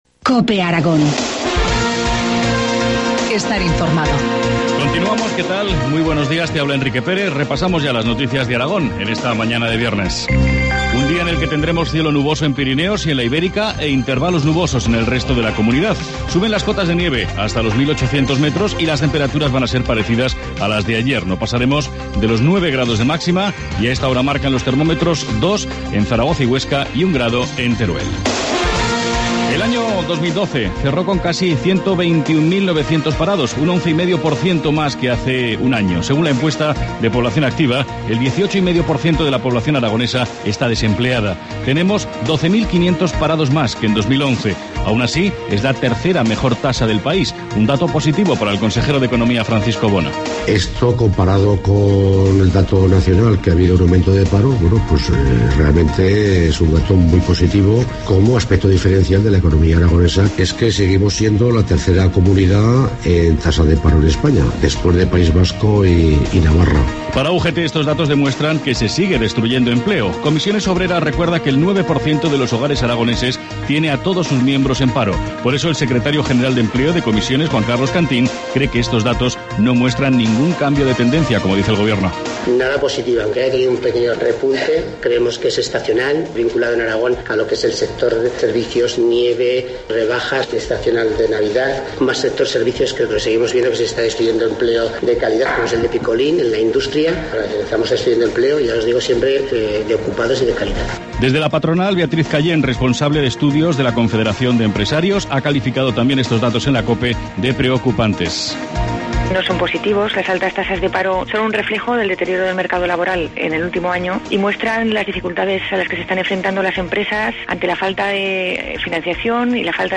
Informativo matinal, viernes 25 de enero, 8.25 horas